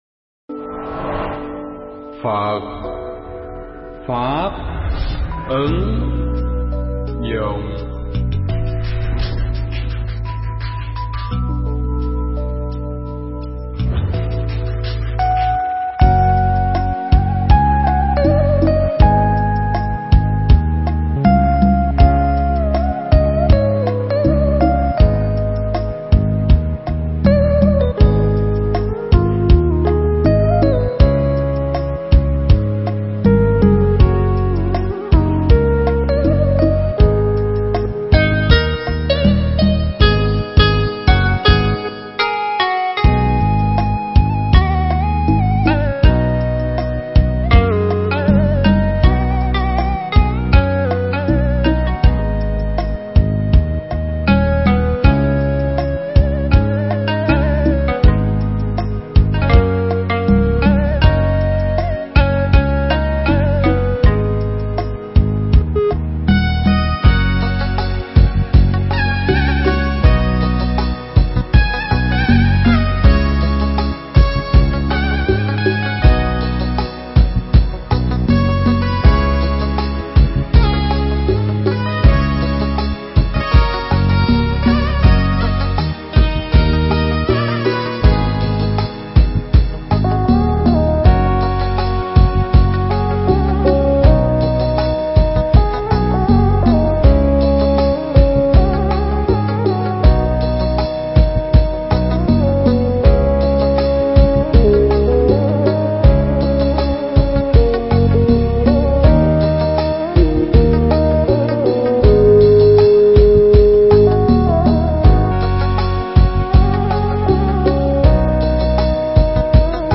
Thuyết pháp Thuận Duyên Và Nghịch Duyên
thuyết giảng tại chùa Phật Huệ (Đức Quốc) ngày 11/10/2015